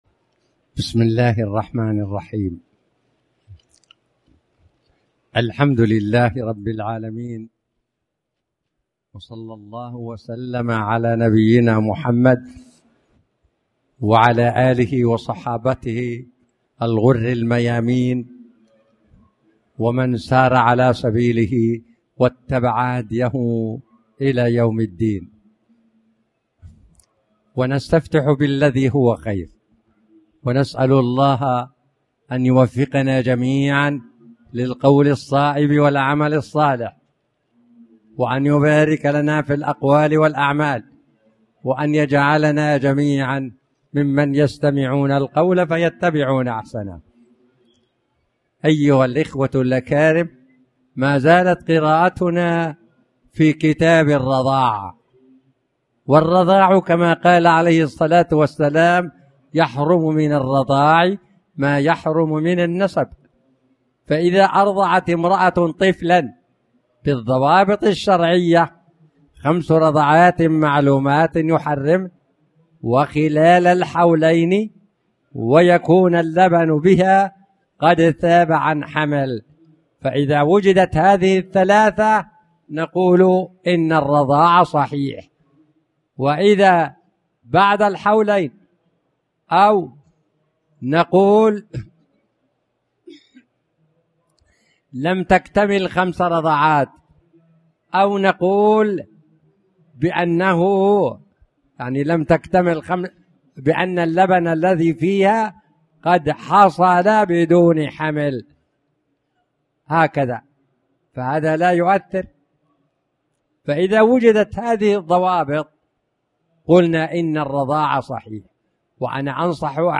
تاريخ النشر ١٩ صفر ١٤٣٩ هـ المكان: المسجد الحرام الشيخ